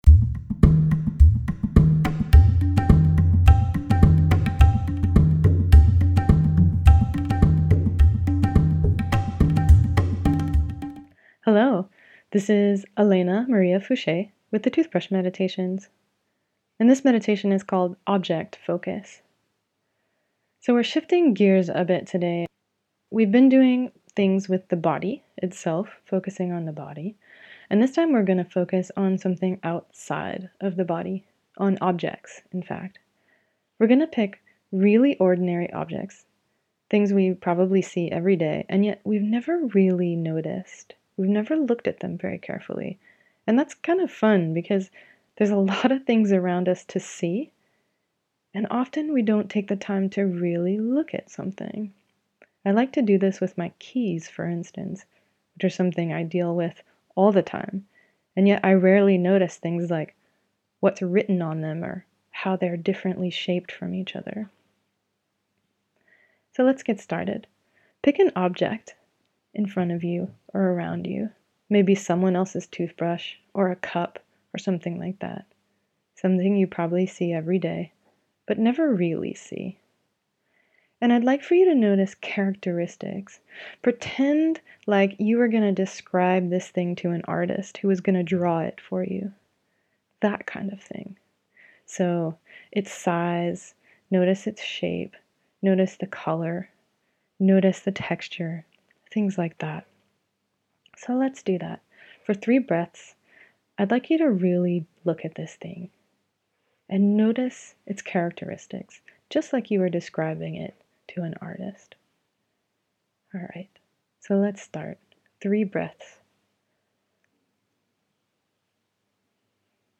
7 guided meditations that you can do while brushing your teeth!